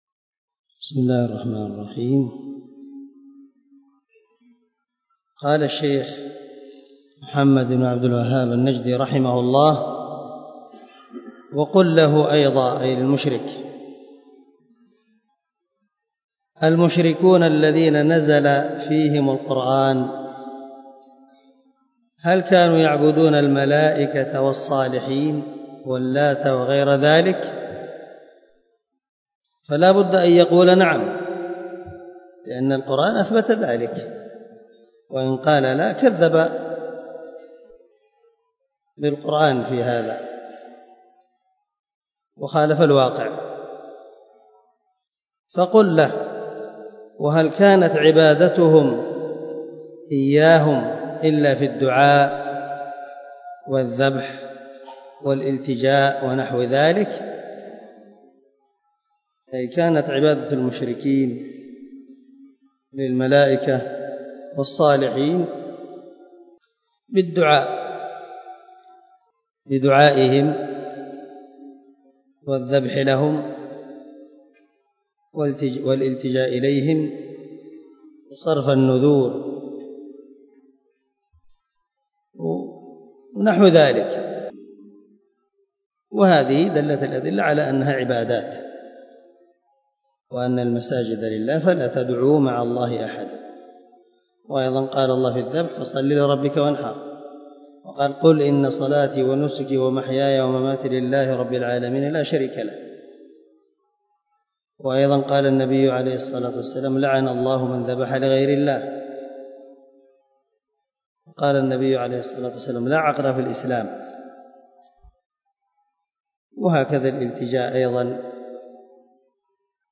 0010 الدرس 9 من شرح كتاب كشف الشبهات